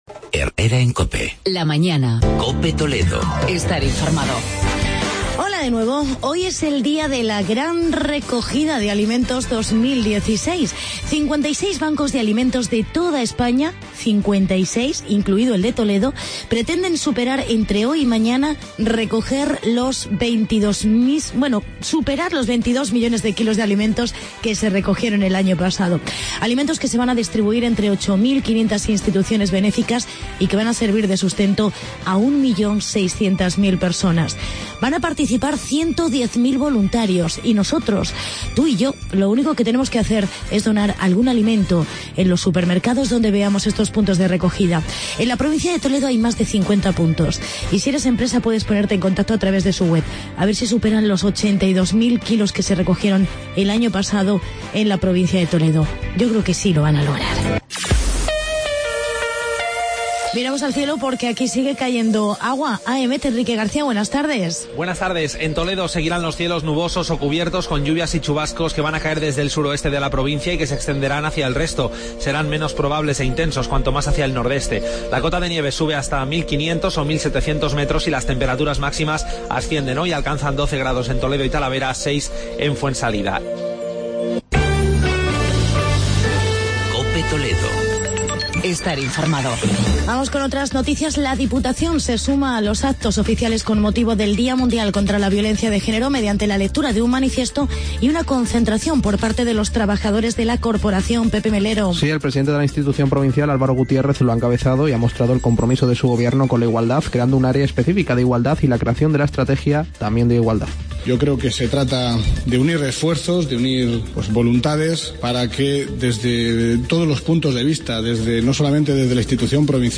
Actualidad y entrevista